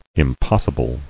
[im·pos·si·ble]